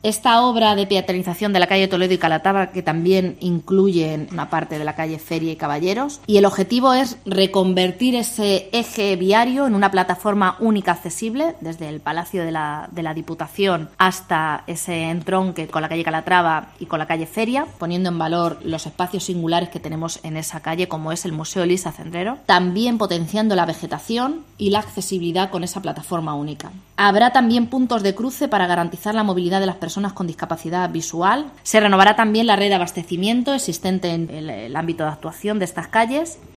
Sara Martínez, portavoz Ayuntamiento CR